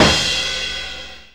CRASH KICK.wav